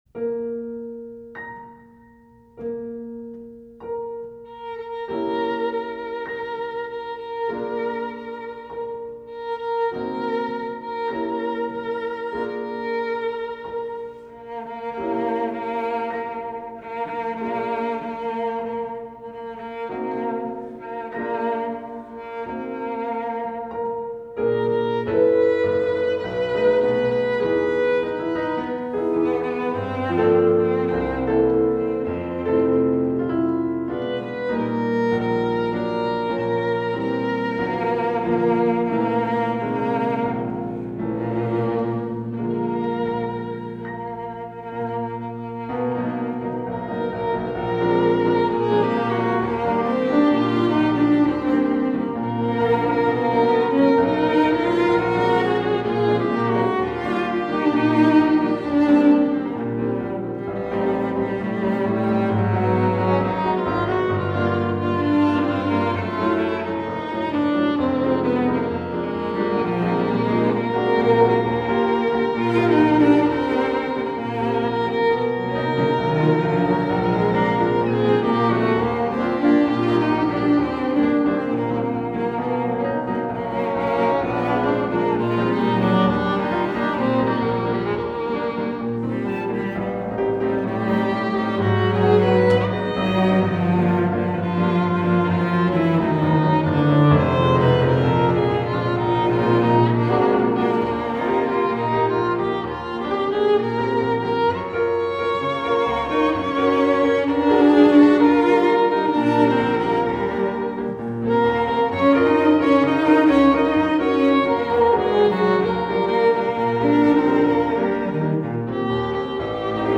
Voicing: Piano Trio